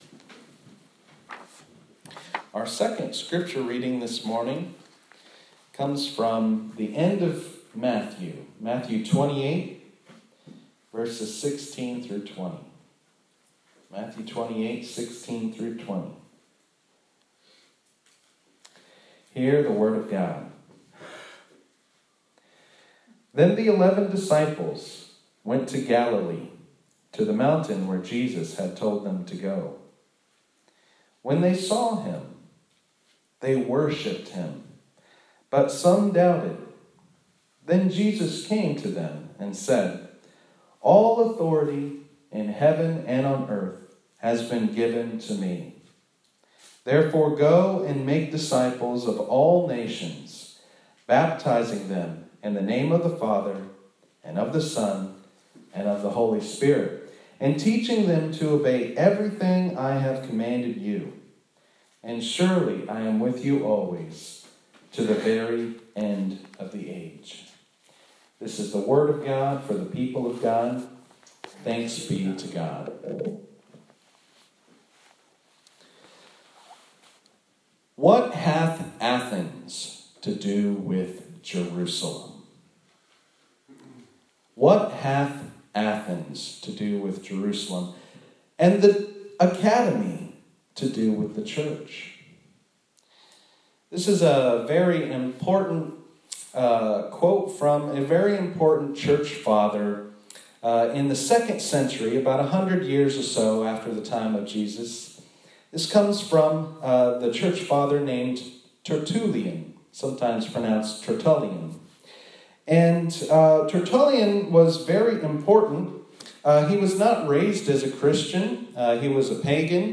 This sermon was preached at Mt. Gilead UMC in Georgetown, KY on Sept 27, 2020.